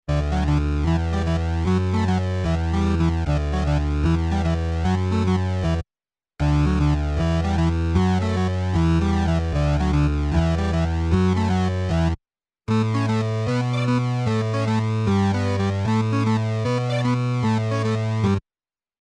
8 bit Gaming Musik
Tempo: schnell / Datum: 15.08.2019